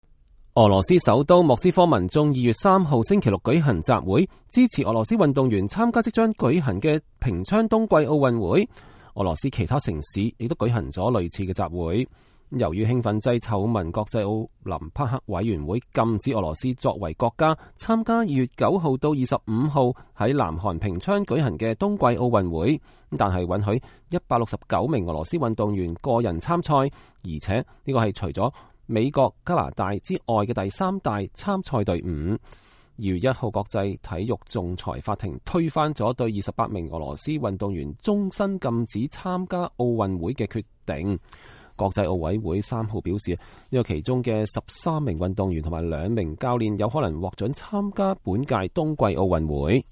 俄羅斯首都莫斯科民眾2月3號星期六舉行集會，支持俄羅斯運動員參加即將舉行的平昌冬季奧運會。